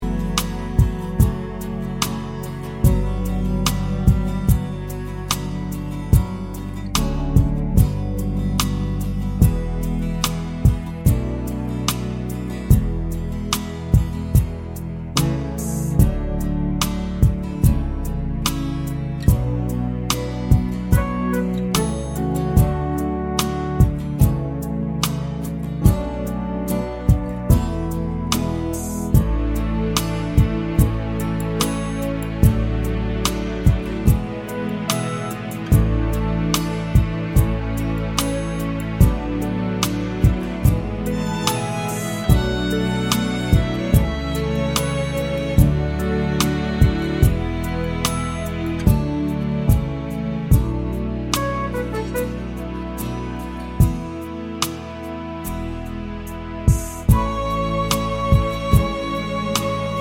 Minus Drums Irish 4:15 Buy £1.50